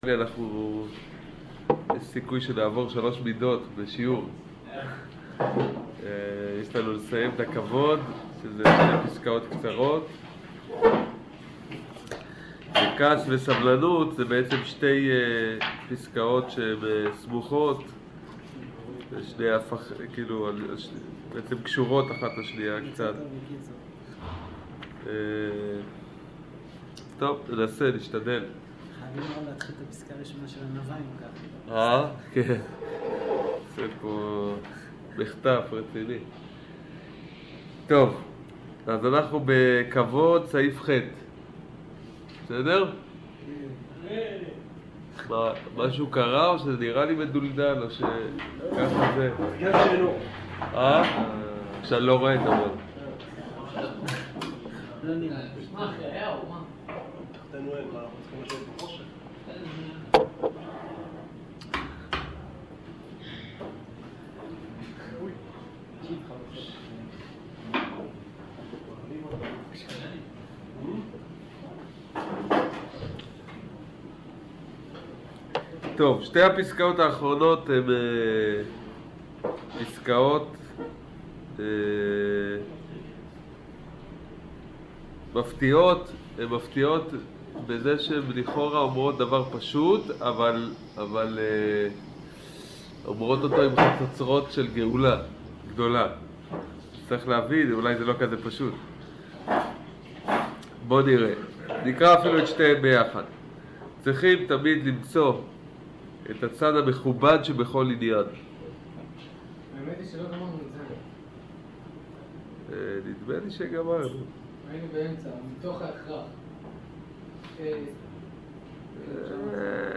שיעור סוף כבוד